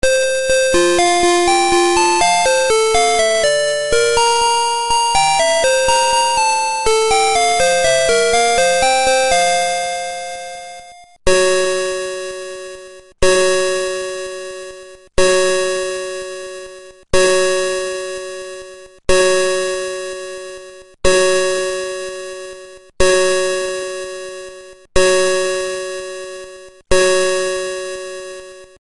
16 Every Day Songs with Clock Chime
• Dual tone melody